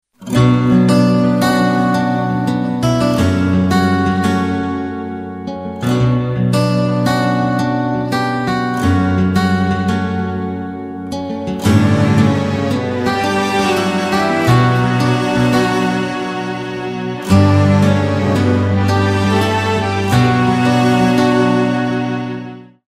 رینگتون نرم و بیکلام